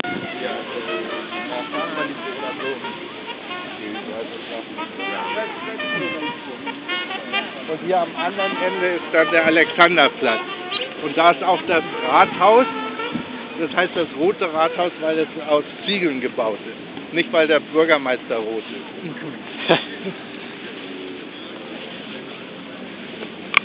Trompete-Musik und das rote Rathaus / Trumpet Music and the Red City Hall